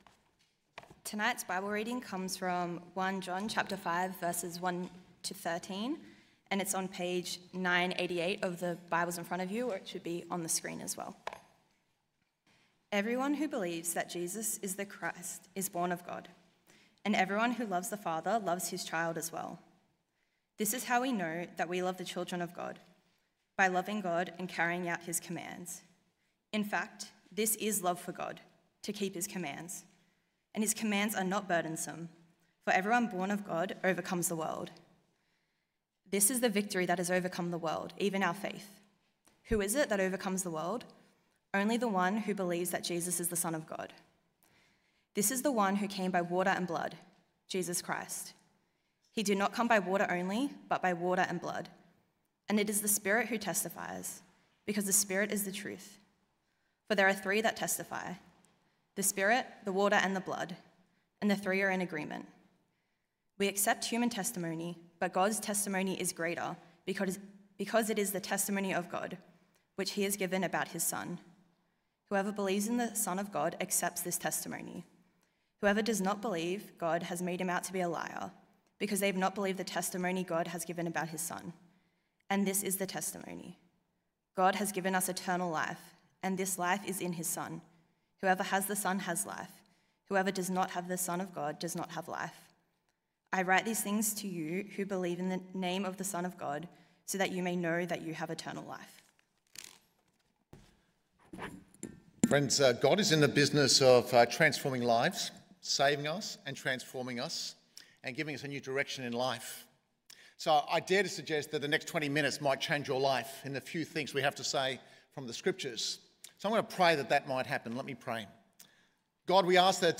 Podcast of recent sermons presented at Sunday Services